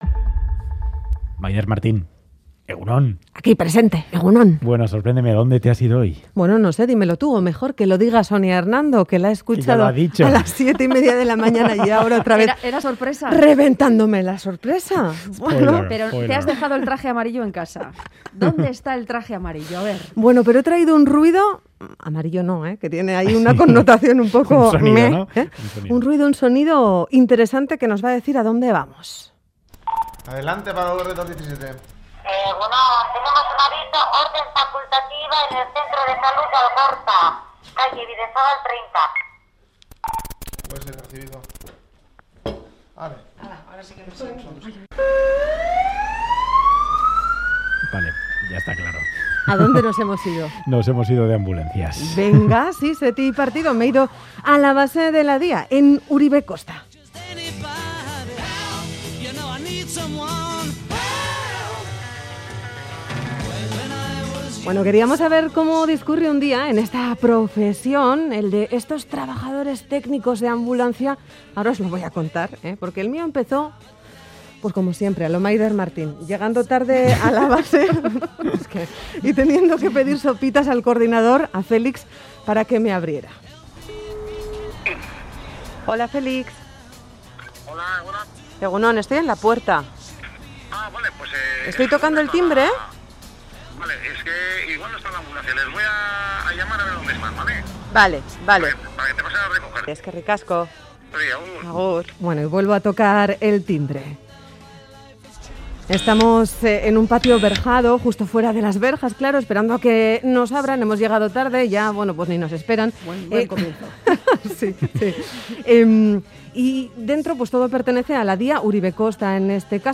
"Boulevard" se monta en una ambulancia de la DYA para conocer su trabajo in situ
"Boulevard" de Radio Euskadi se sube a una ambulancia de la DYA para vivir el día a día de los sanitarios en primera persona.